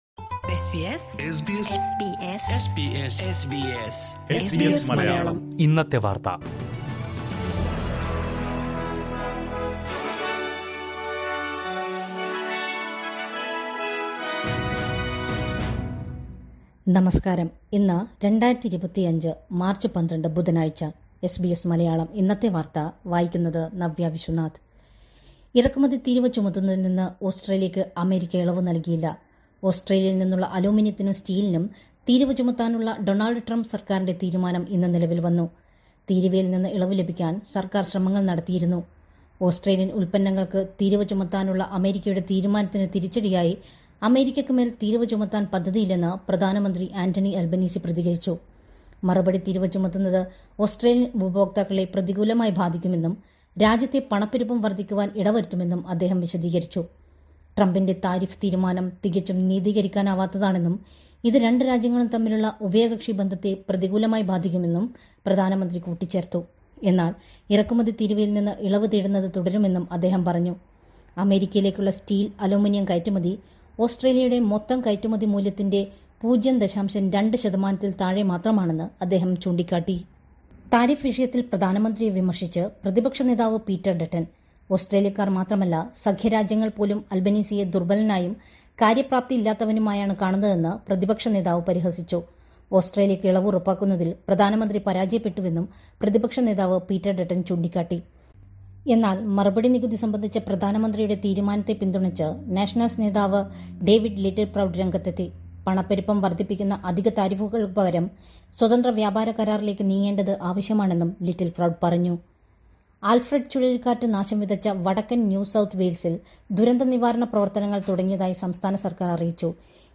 2025 മാര്‍ച്ച് 12ലെ ഓസ്‌ട്രേലിയയിലെ ഏറ്റവും പ്രധാന വാര്‍ത്തകള്‍ കേള്‍ക്കാം...